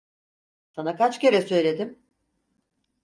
Pronounced as (IPA) /kɑt͡ʃ/